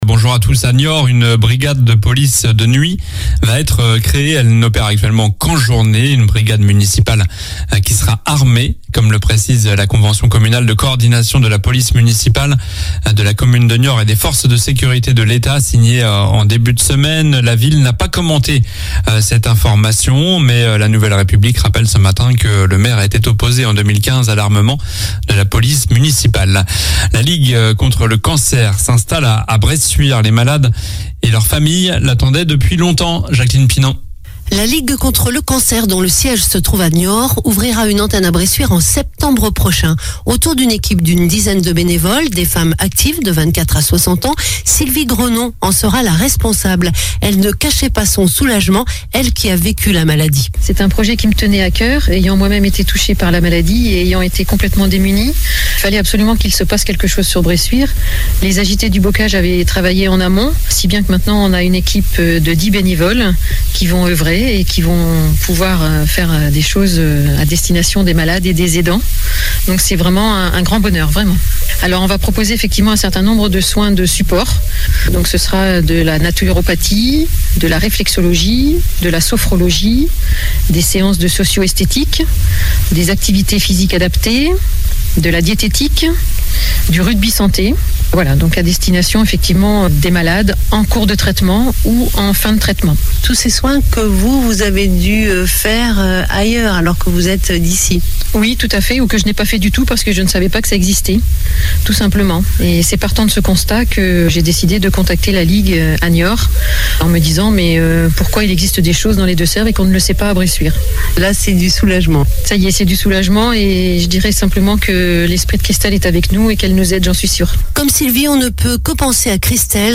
Journal du jeudi 7 juillet (matin)